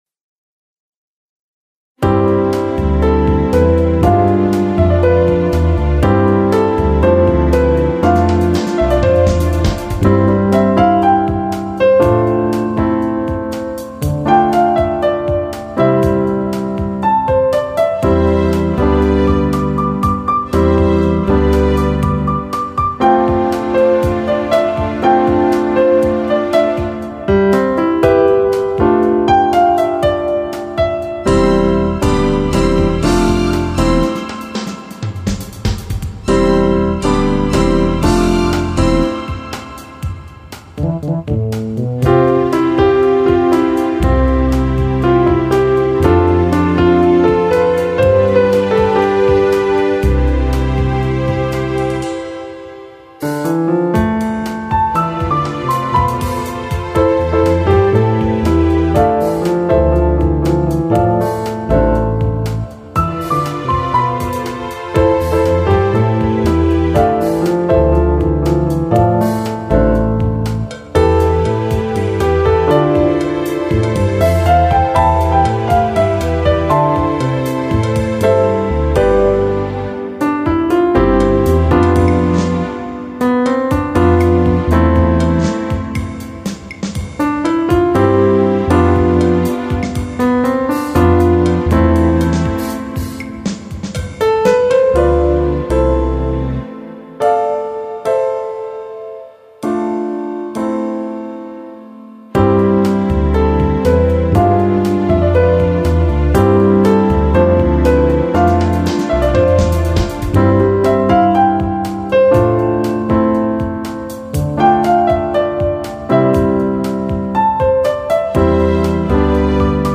EASY LISTENING MUSIC ; JAZZ